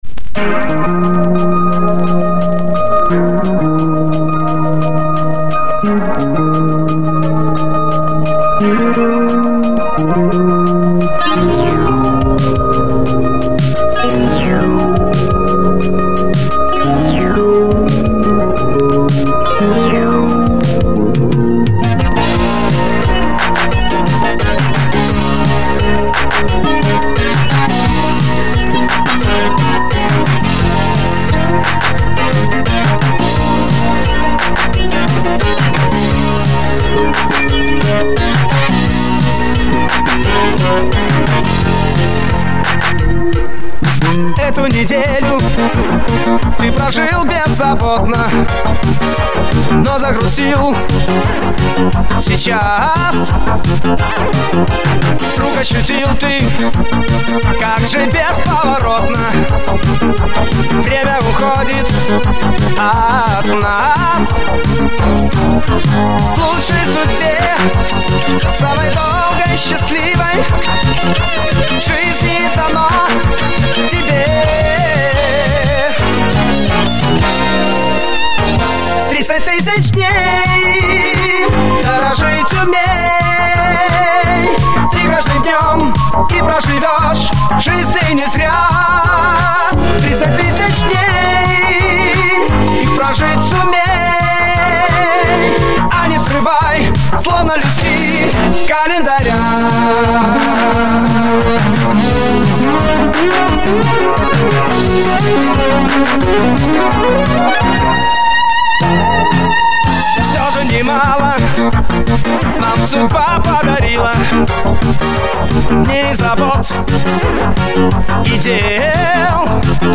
ОЦИФРОВКА С ПЛАТИНКИ